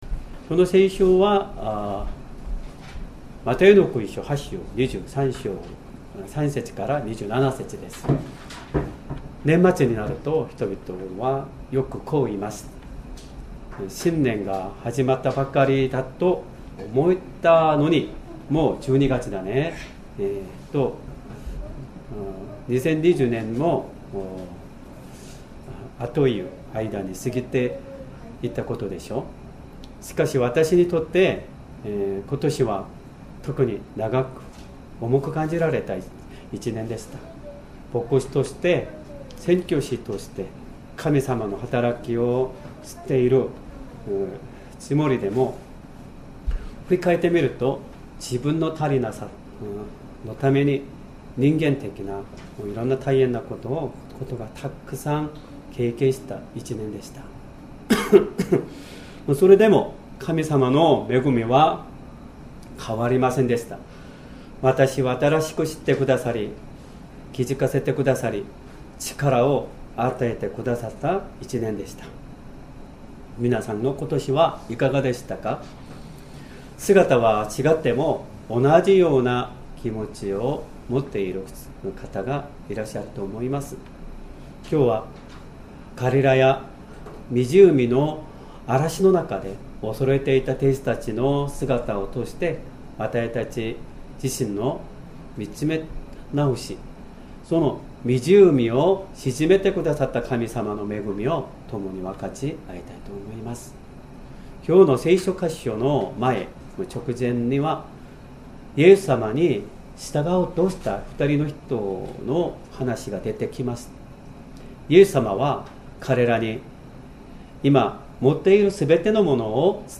Sermon
2025年12月28日 主日礼拝 説教 「風と湖を凪とされた方 」 聖書 マタイの福音書 8章 23～27節 8:23 それからイエスが舟に乗られると、弟子たちも従った。